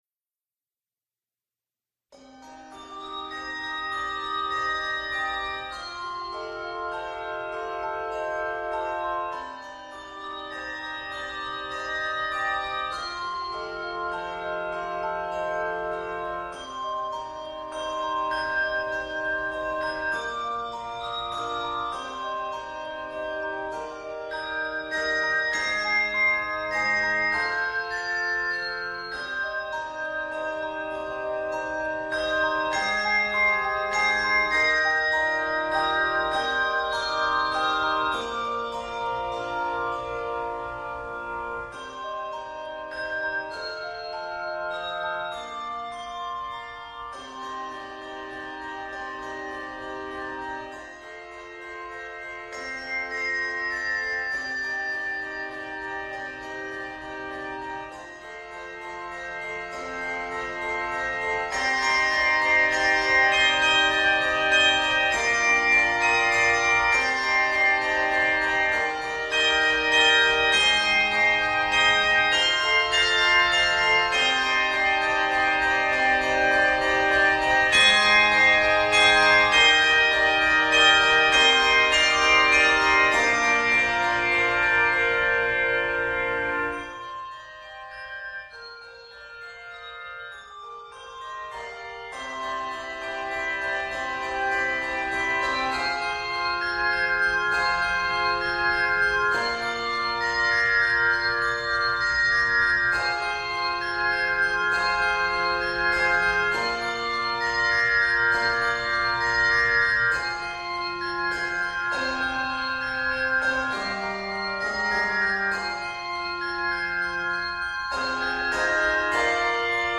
English folk tune
Arranged in c minor, f minor, and Bb minor.